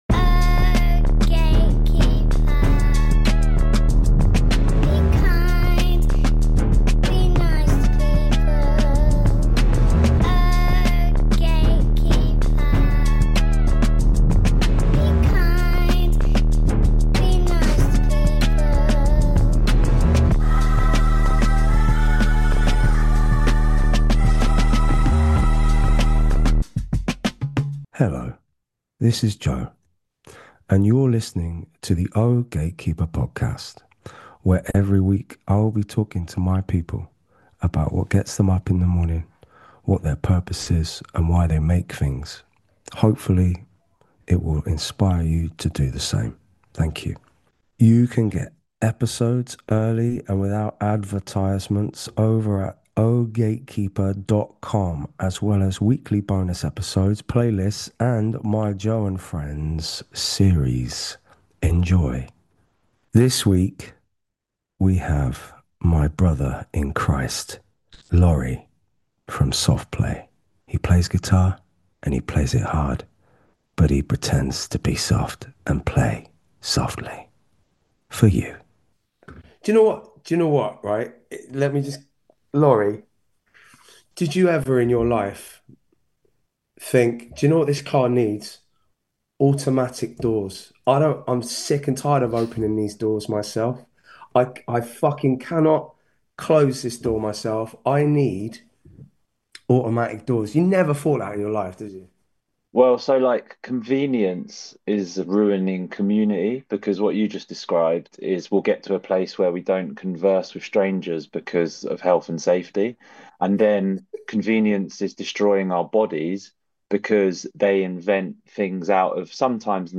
Each week Joe sits down for intimate one-to-one conversations with some of his favourite people in the world: be it artists, musicians or cultural icons, to explore their compulsion to create art.
This was a beautiful and honest conversation between two good friends. Laurie touches on everything from parenting, hobbies and music to how to use a T-Bone steak as a weapon.